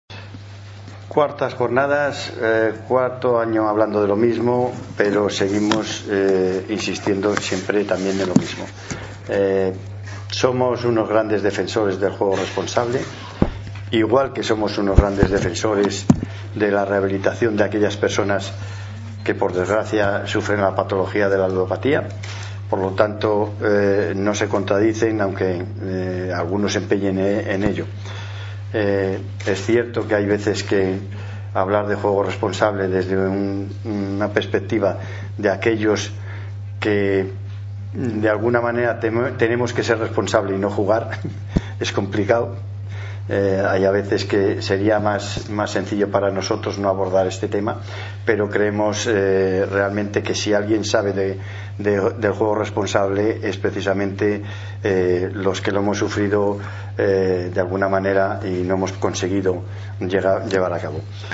FEJAR promueve una nueva jornada científica sobre la problemática social del Juego